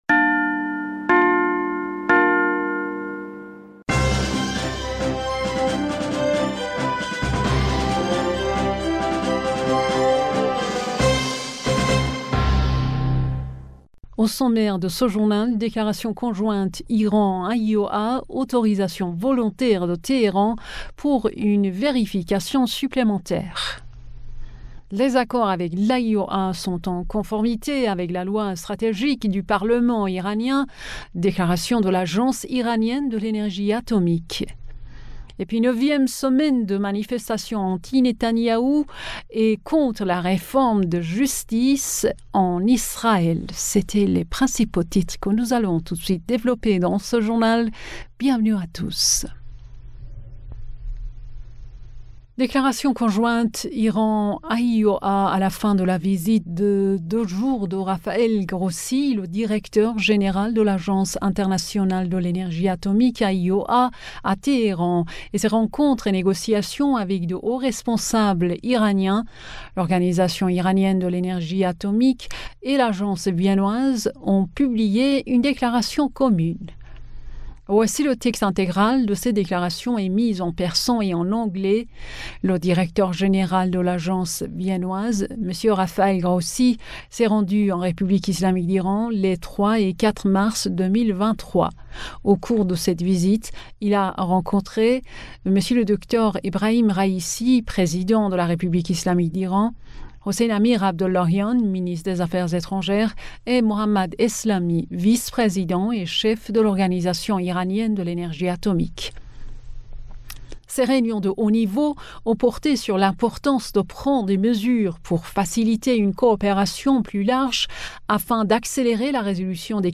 Bulletin d'information du 05 Mars